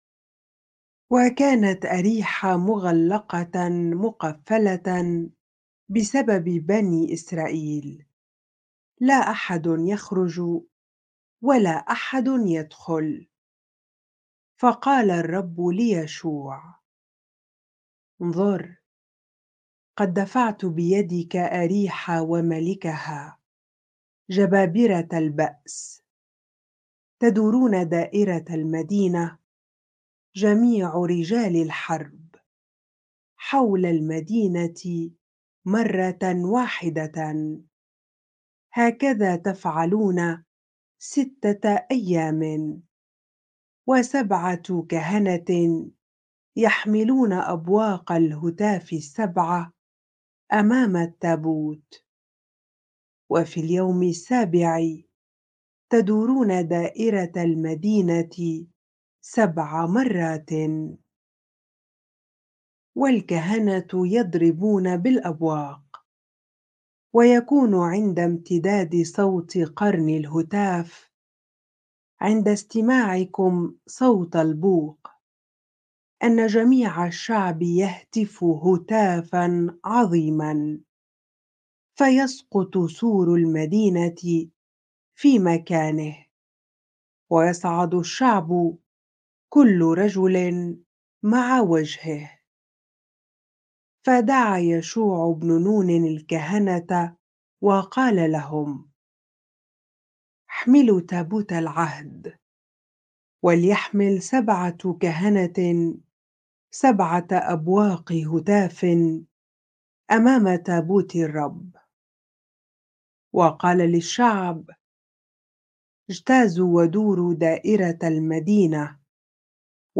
bible-reading-joshua 6 ar